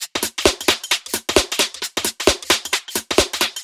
Index of /musicradar/uk-garage-samples/132bpm Lines n Loops/Beats